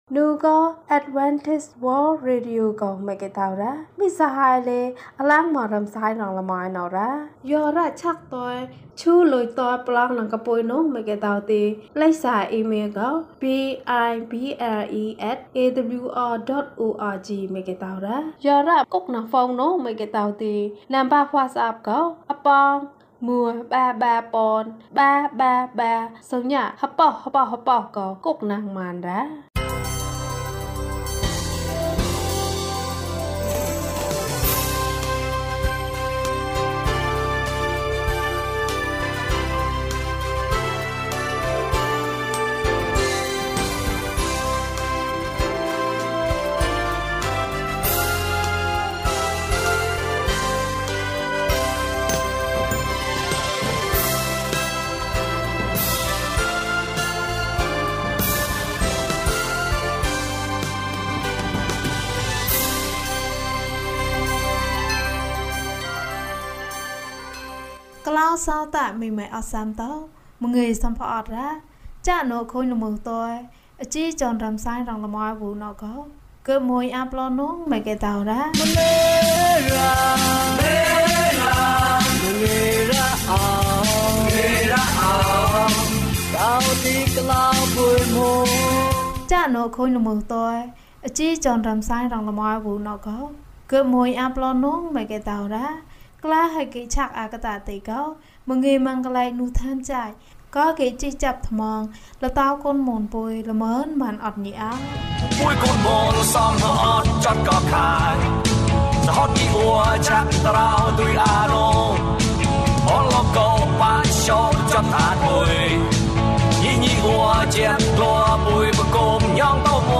သခင်ယေရှုက “ငါ့ထံသို့လာလော့” ဟုမိန့်တော်မူ၏။ ကျန်းမာခြင်းအကြောင်းအရာ။ ဓမ္မသီချင်း။ တရားဒေသနာ။